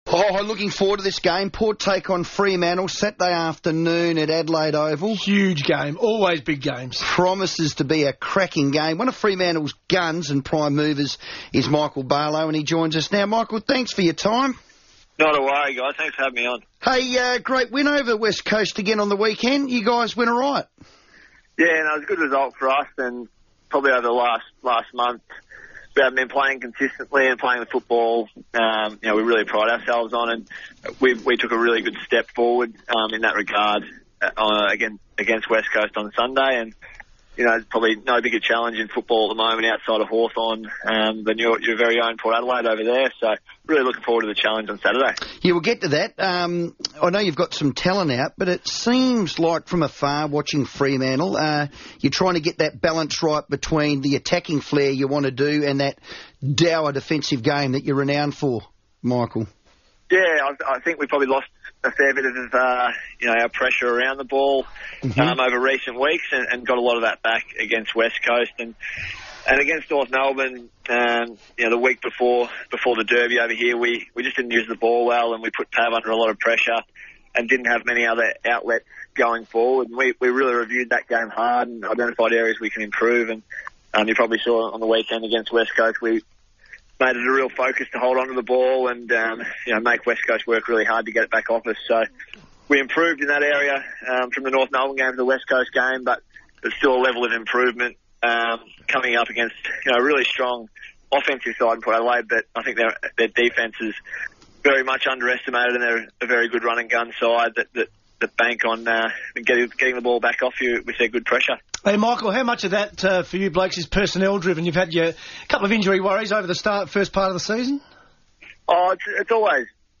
Michael Barlow speaks to FIVEaa about trying to get back to Fremantle's best.